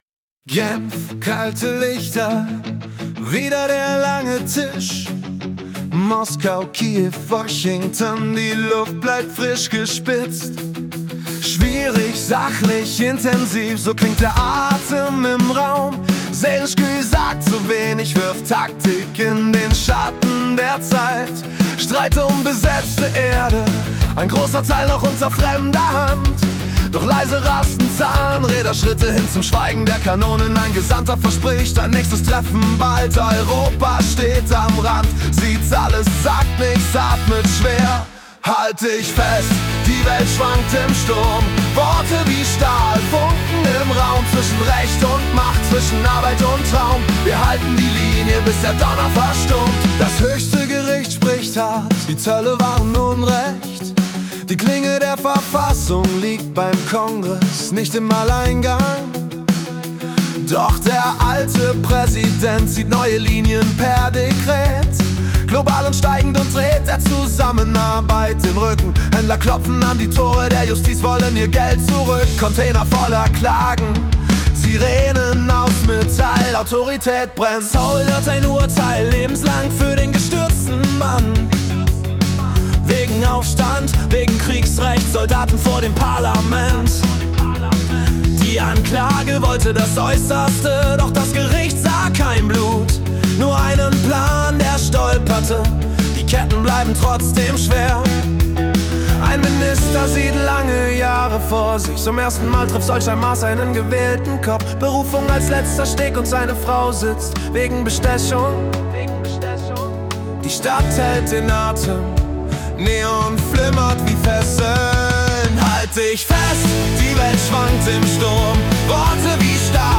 Februar 2026 als Rock-Song interpretiert.